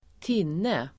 Uttal: [²t'in:e]